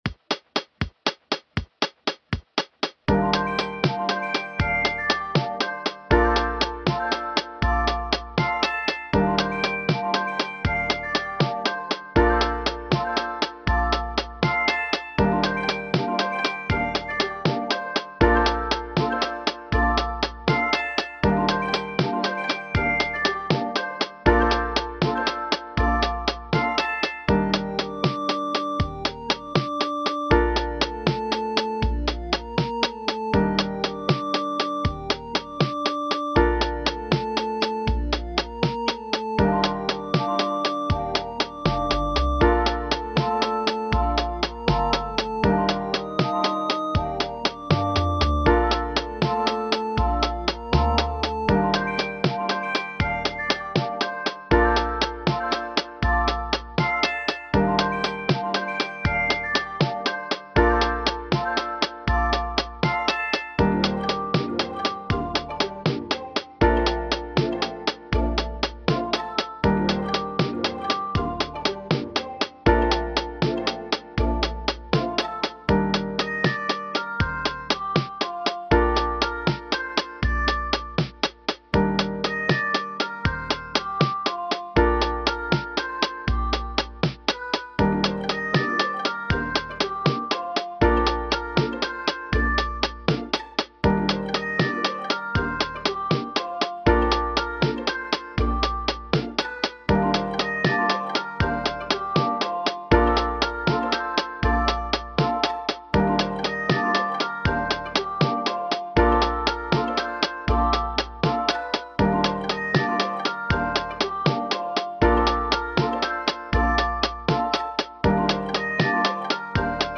8 bit summer memory